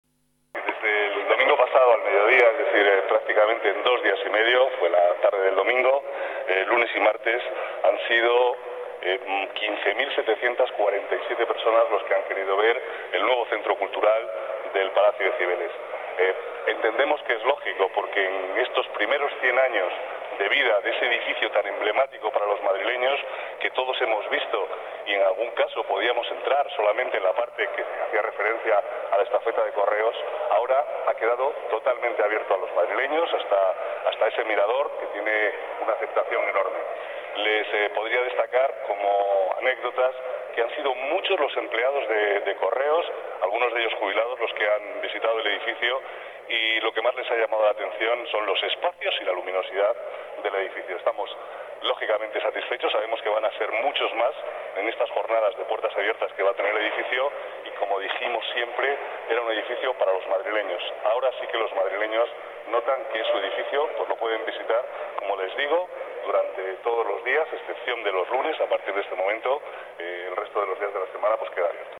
Nueva ventana:Palabras del vicealcalde, Manuel Cobo.